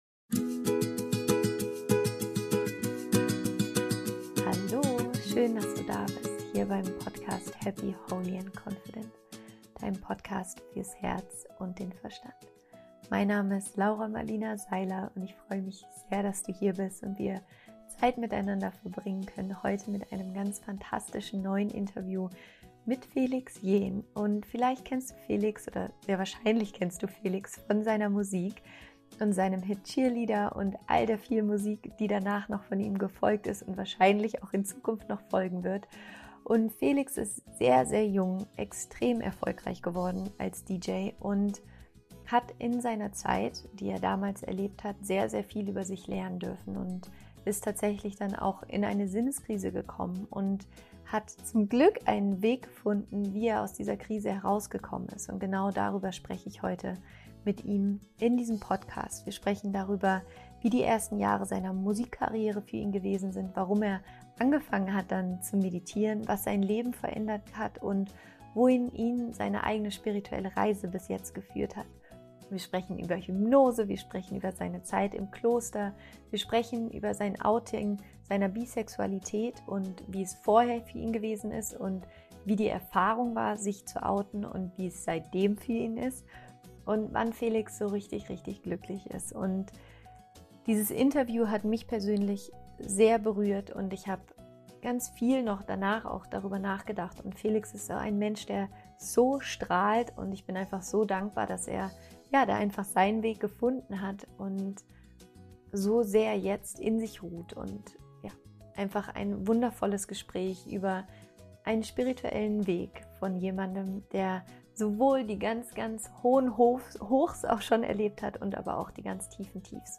Wie du nach einer Sinnkrise wieder Glück findest – Interview Special mit Felix Jaehn